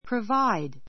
prəváid